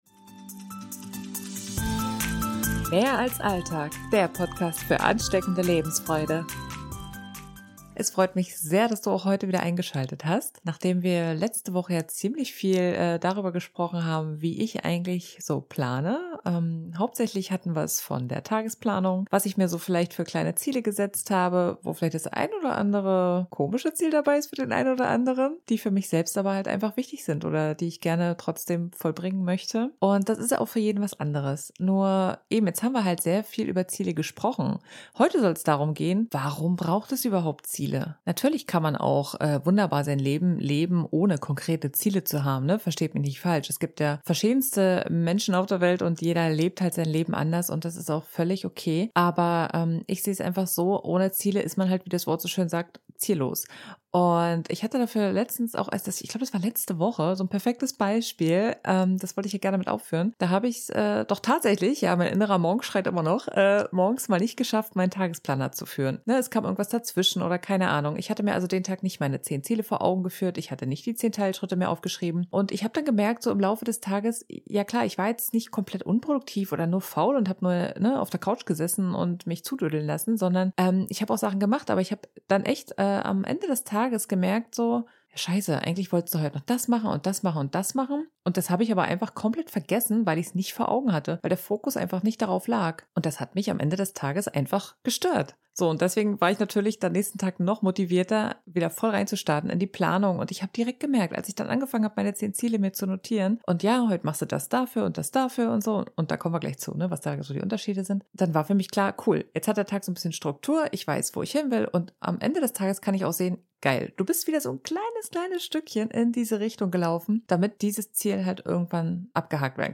Heute widmen wir uns dem großen Thema Ziele und was ist eigentlich der Unterschied zu Wünschen und Planung? Intro-/Outromusik des Podcasts: